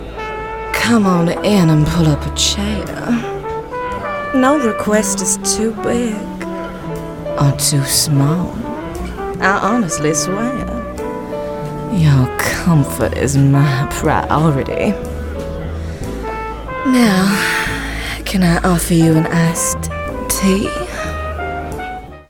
Her sound is very easy on the Ear. She also has an ability to cover a very wide age range and creates fantastically full and rich characters completely different from one to the other perfect for Gaming and Animation.